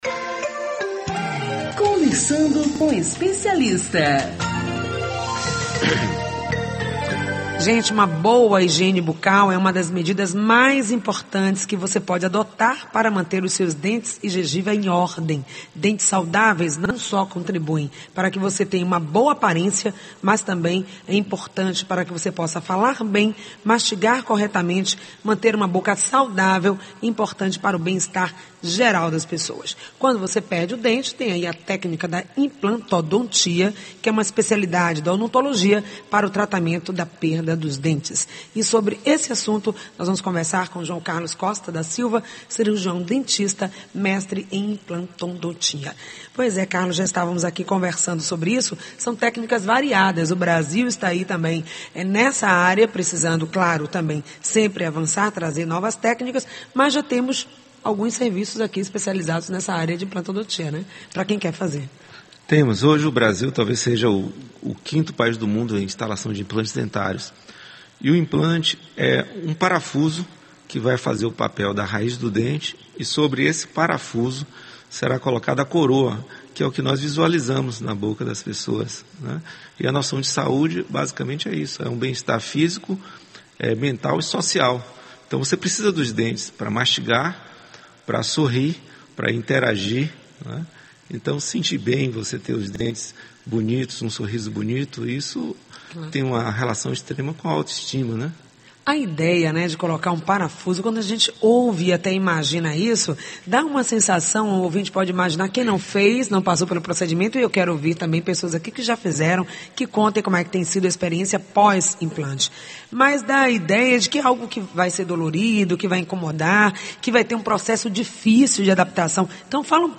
O programa Saúde No Ar vai ao ar de segunda à sexta-feira, das 10 às 11h, pela Rede Excelsior de comunicação: AM840, AM Recôncavo 1.460 e FM 106.1 e também pela Rádio Saúde no ar, (aplicativo gratuito).